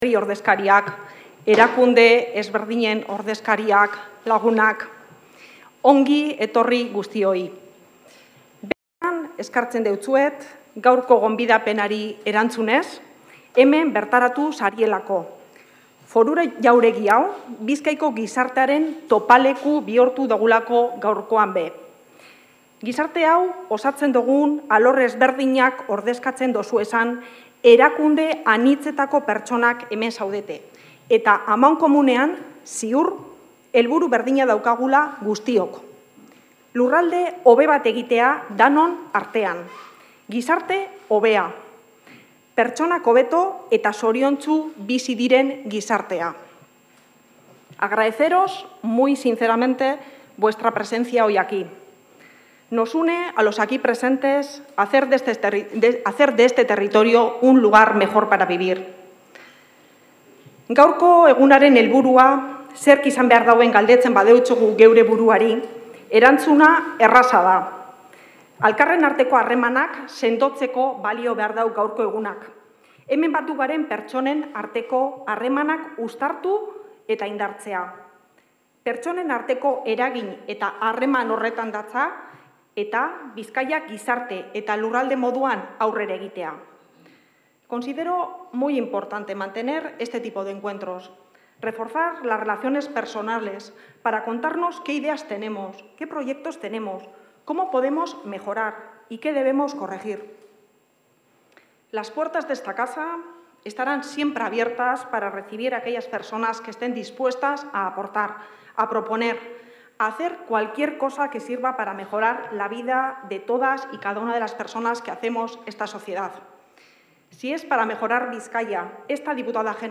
La diputada general de Bizkaia, Elixabete Etxanobe, ha señalado en la recepción institucional con motivo de la festividad de San Ignacio que el territorio necesita...